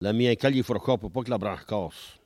Maraîchin
Locution